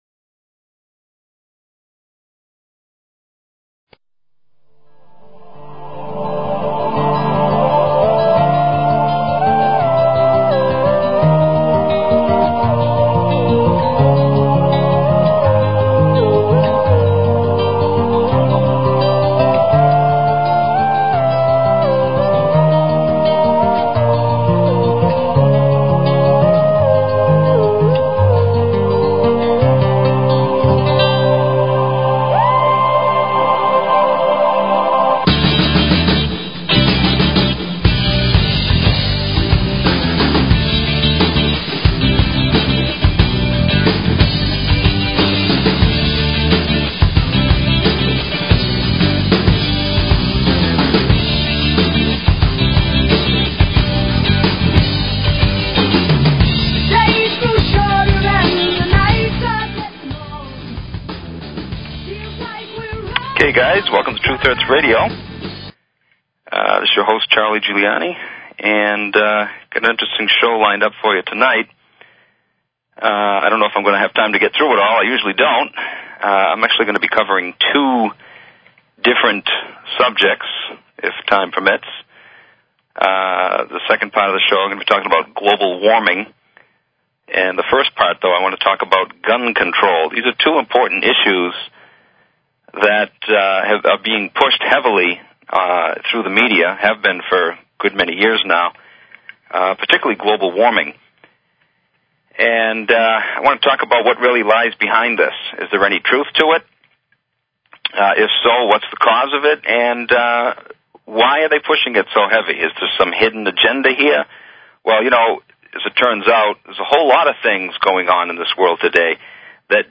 Talk Show Episode, Audio Podcast, Truth_Hertz_Radio and Courtesy of BBS Radio on , show guests , about , categorized as